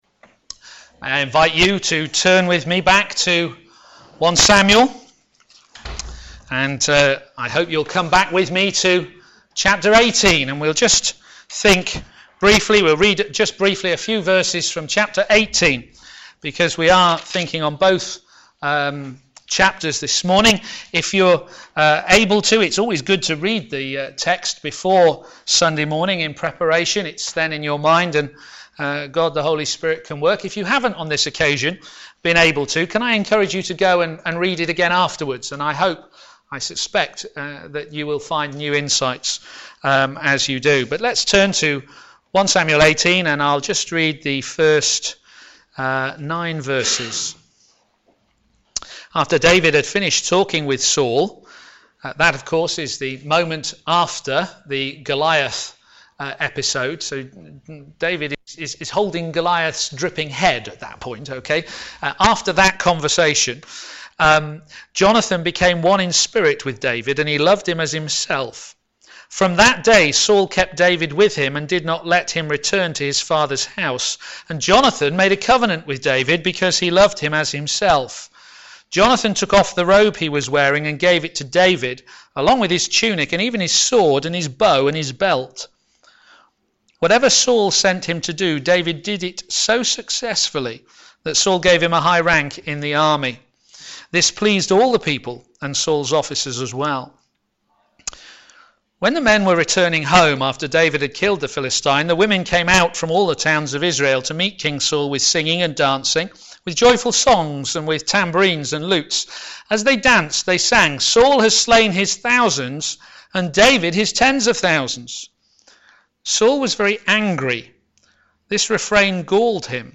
Media Library Media for a.m. Service on Sun 17th May 2015 10:30 Speaker
A man after God's own Heart Theme: Envy? Esteem? Protection! Sermon